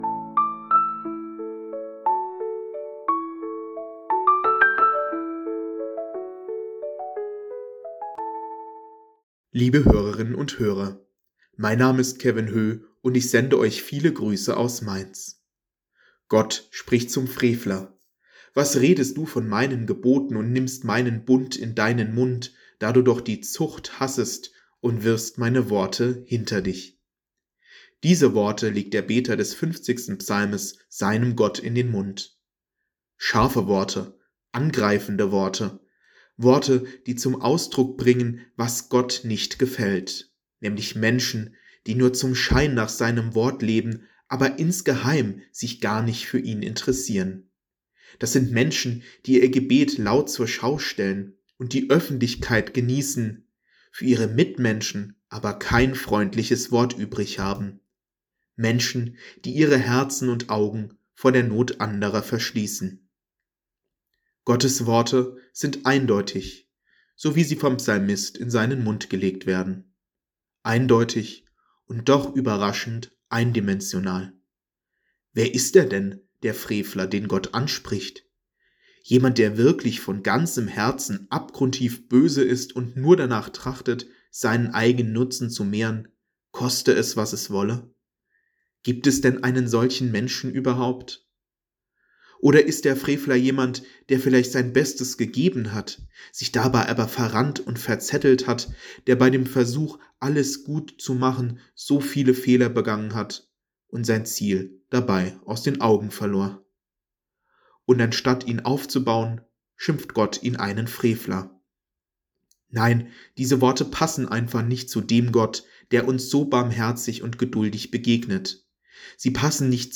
Losungsandacht für Donnerstag, 05.06.2025